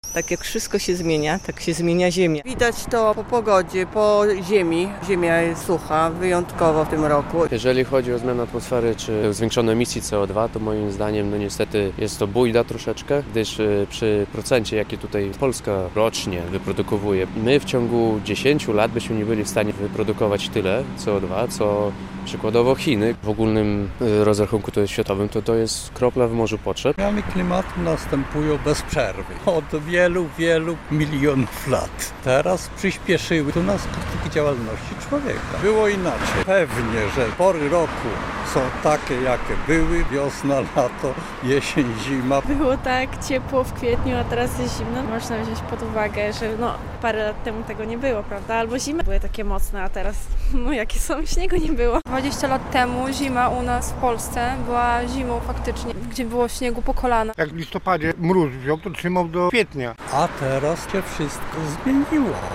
Zapytaliśmy białostoczan, co wiedzą o zmianach klimatycznych.